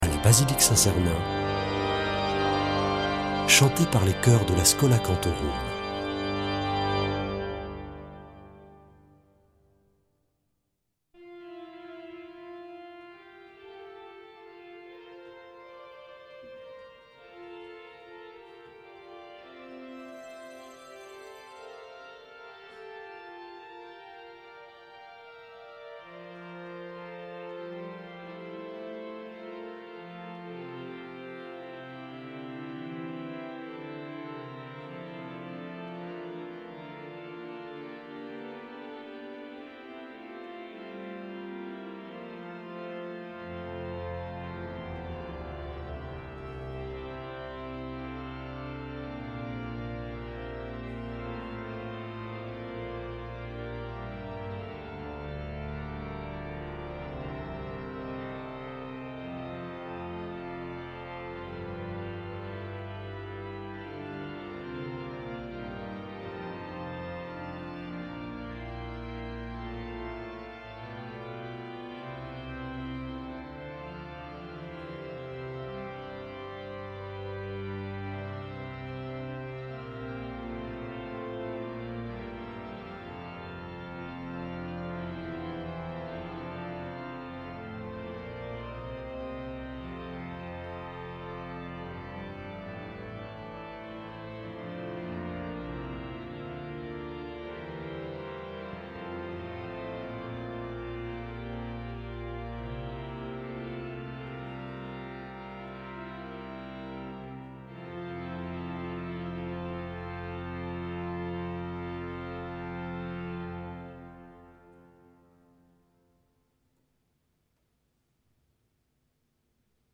Vêpres de Saint Sernin du 13 oct.
Une émission présentée par Schola Saint Sernin Chanteurs